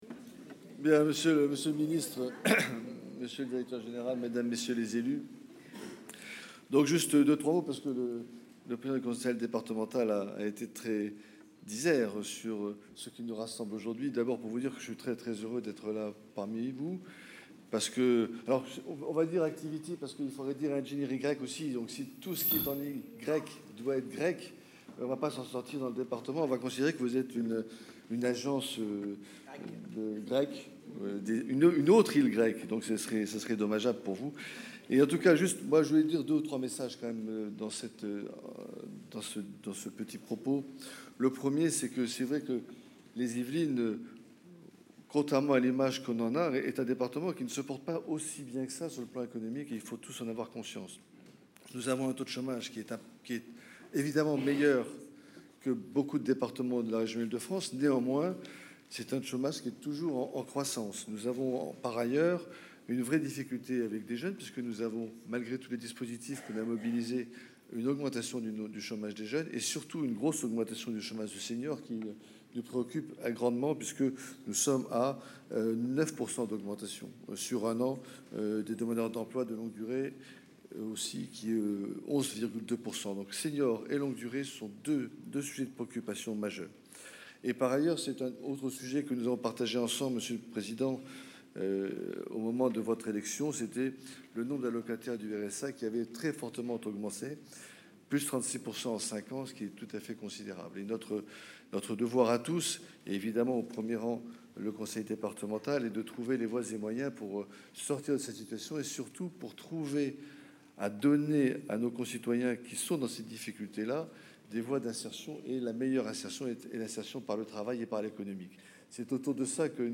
Inauguration d’Activity, les discours :
Erard Corbin de Mangoux, le préfet des Yvelines :
Discours-de-Mr-Erard-Corbin-de-Mangoux1.mp3